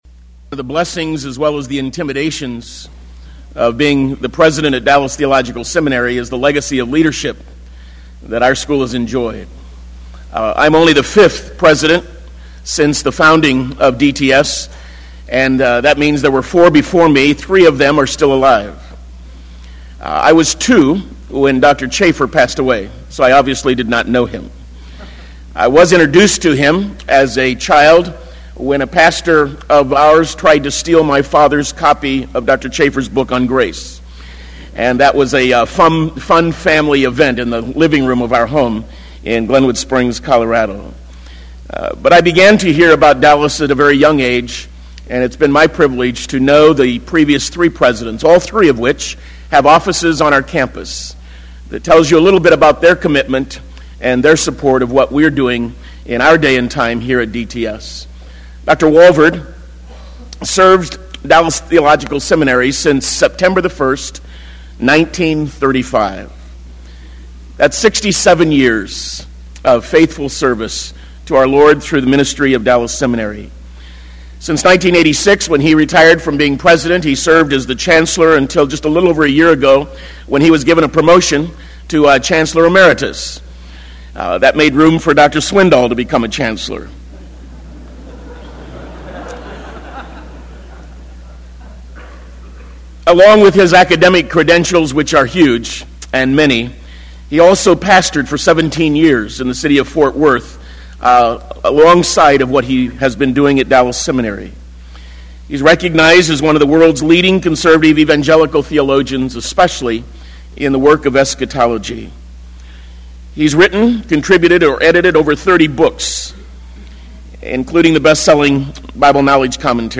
In this sermon, the speaker emphasizes the importance of preaching the word of God effectively. He references 2 Timothy chapter 4, where Paul outlines the elements of effective speaking.